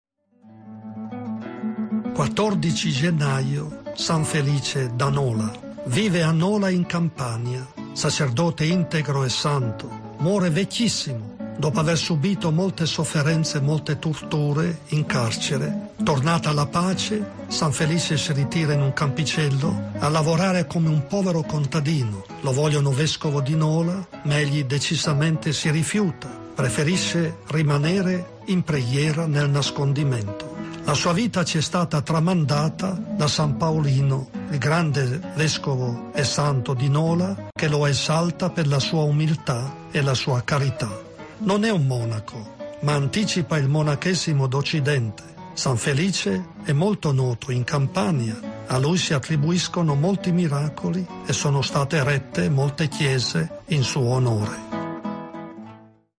Liturgia del Santo Patrono
Domenica 25, la Propositura di S. Felice a Ema, ha festeggiato la ricorrenza di S. Felice Santo Patrono.